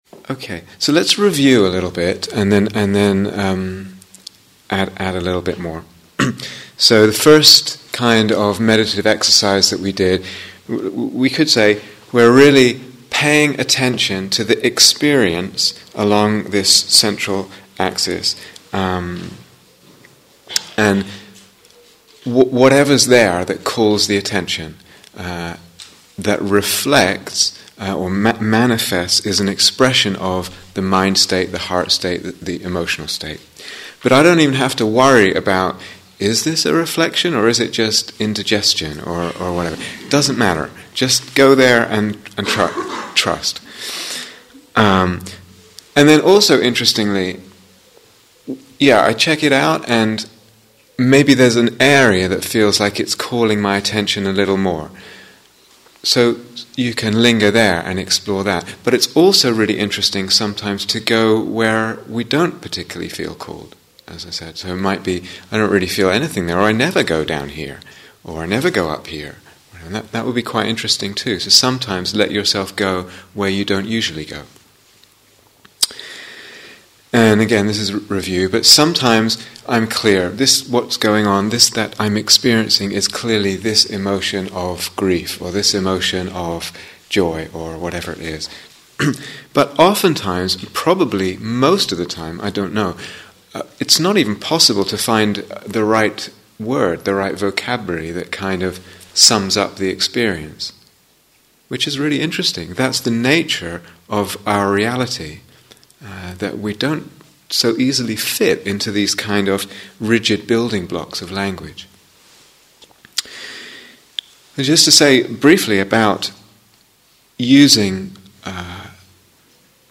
Working with the Emotional Body (Instructions and Guided Meditation: Day Three)